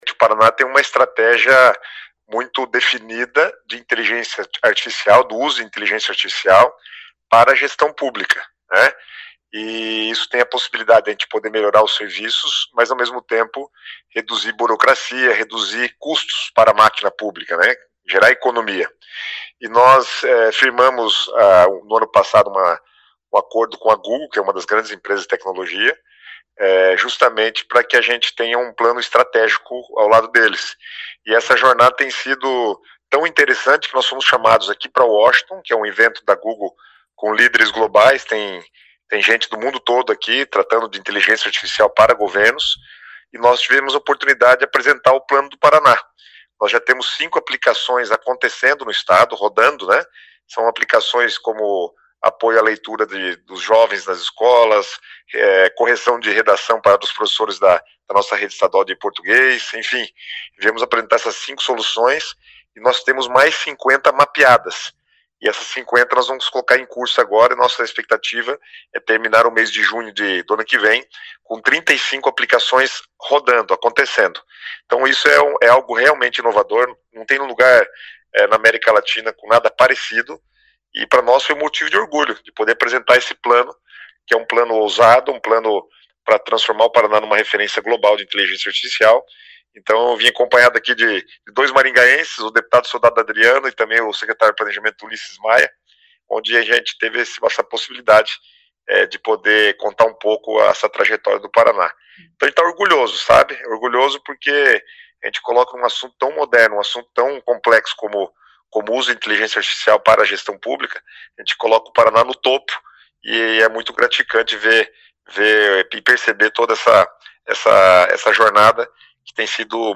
Ouça o que diz o secretário Guto Silva: